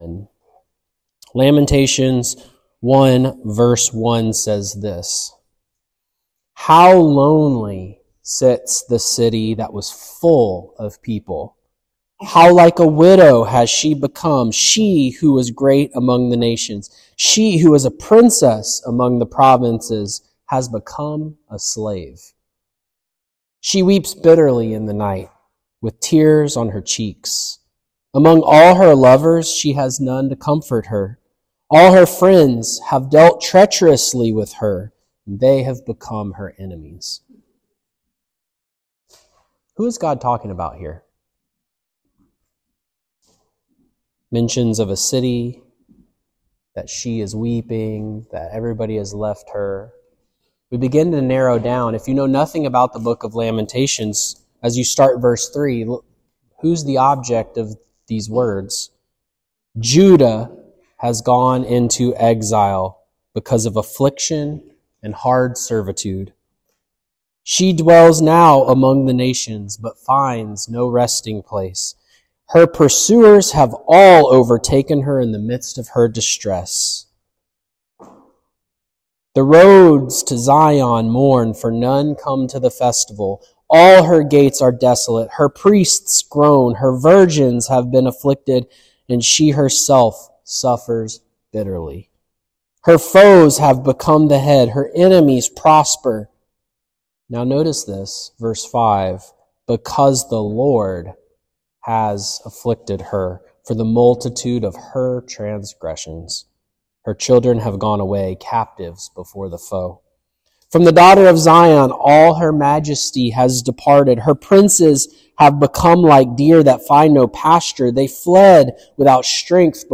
In this lesson, we will explore the message of Lamentations, its applications, and why it is an essential lesson for everyone.